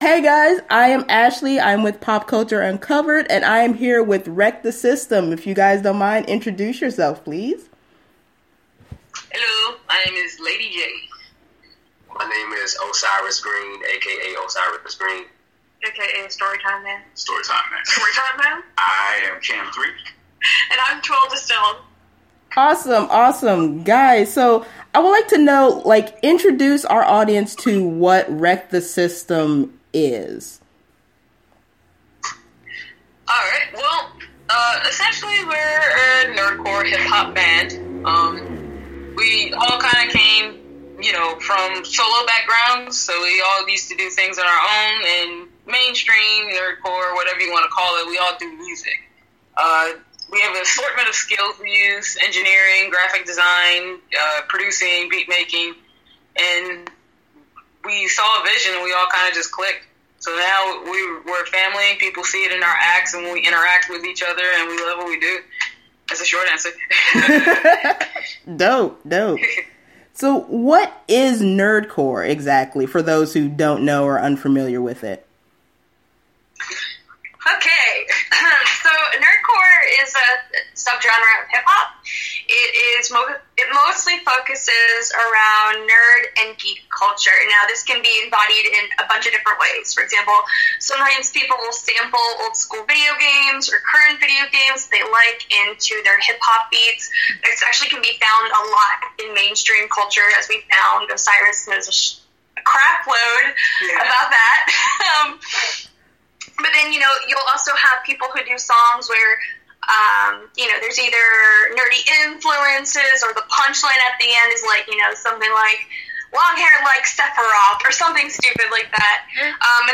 wts_interview_edit.mp3